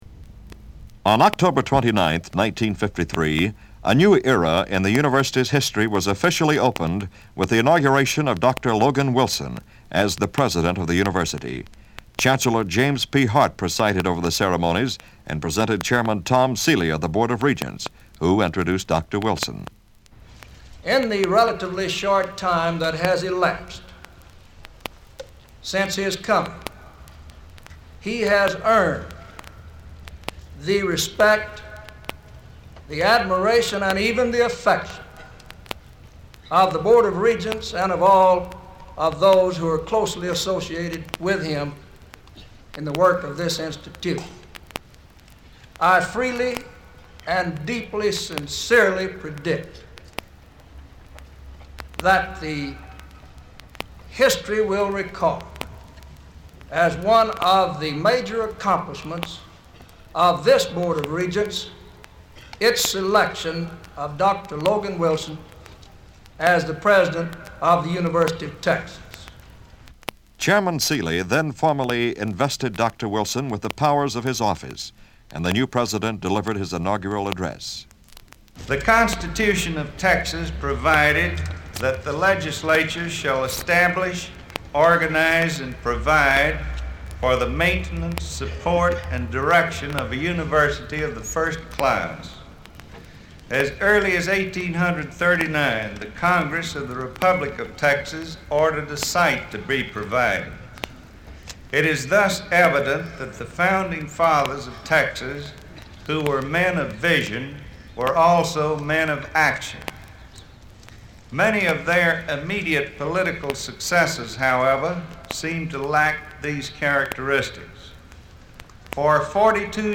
Inauguration speech by UT President Logan Wilson: October 29, 1953
president-logan-wilsonPresident Logan Wilson: On October 29, 1953, in a ceremony on the steps of the Main Building, Logan Wilson was formally installed as President of the University of Texas. Part of his inauguration speech is here.
logan-wilson-inauguration-october-1953.mp3